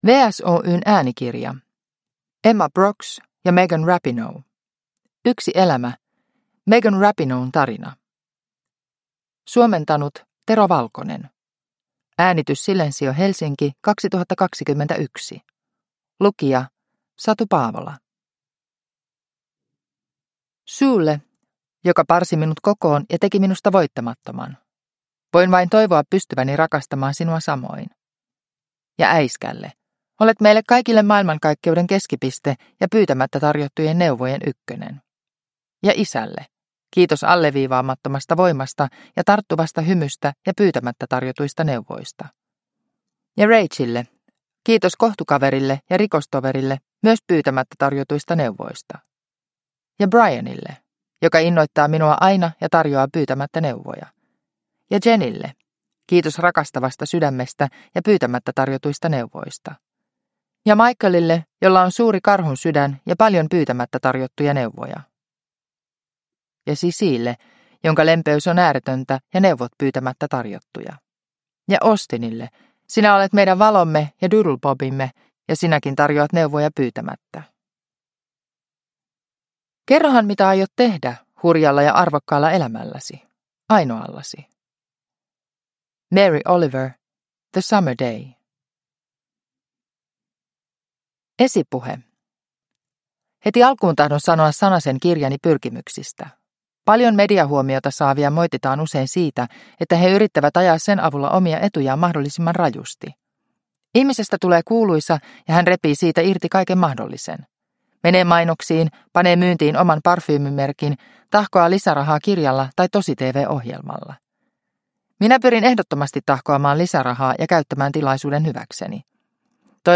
Yksi elämä – Ljudbok